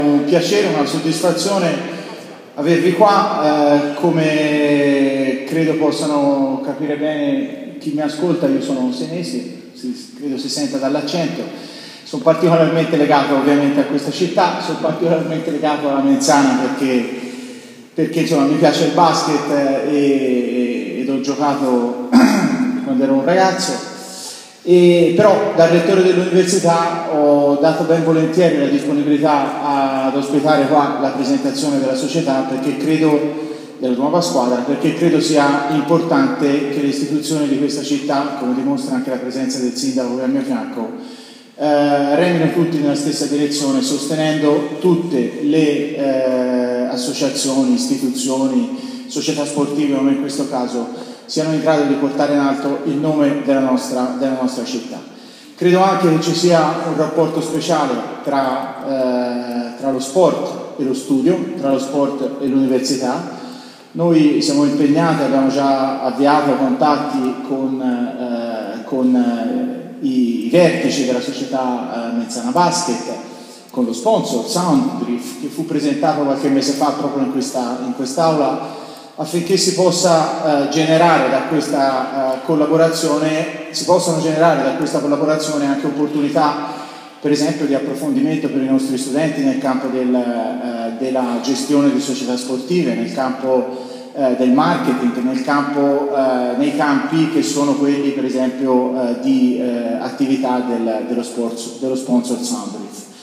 Le parole del Rettore UniSi Frati alla presentazione della squadra